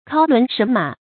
尻輪神馬 注音： ㄎㄠ ㄌㄨㄣˊ ㄕㄣˊ ㄇㄚˇ 讀音讀法： 意思解釋： 以尻為車而神游。